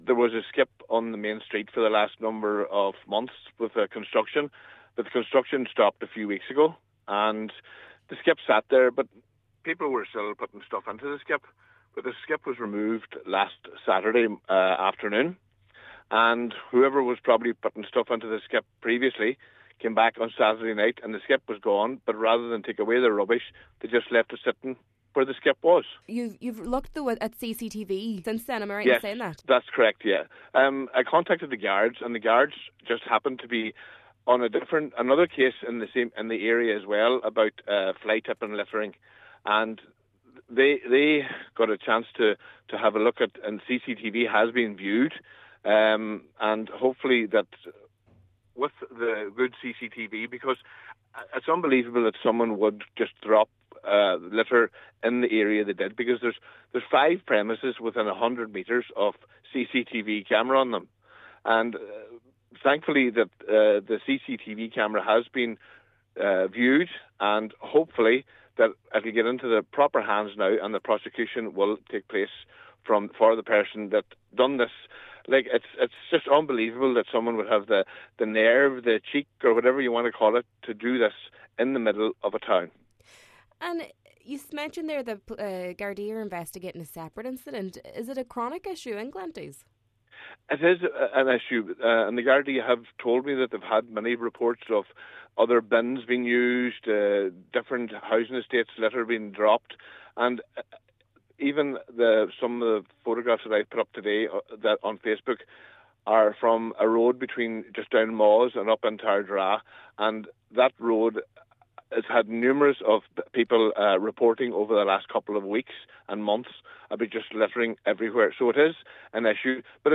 Cllr Carr said he was shocked by the brazen act: